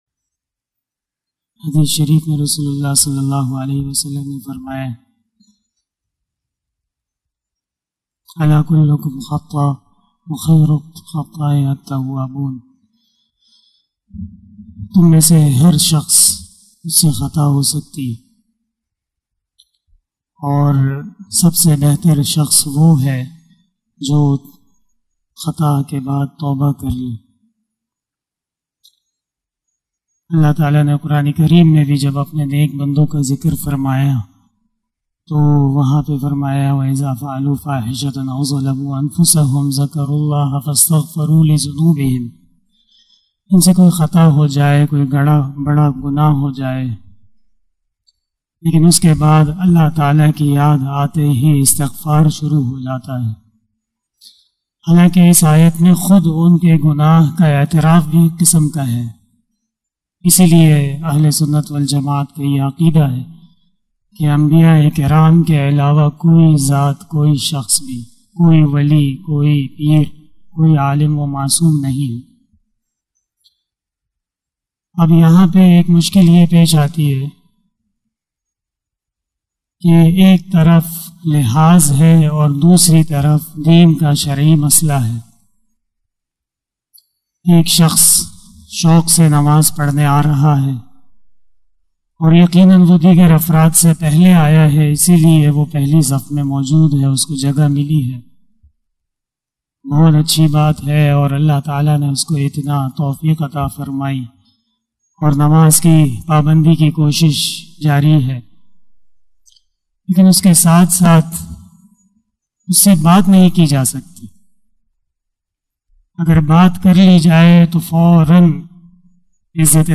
026 After Asar Namaz Bayan 14 June 2021 ( 04 Zulqadah 1442HJ) tuesday
بیان بعد نماز عصر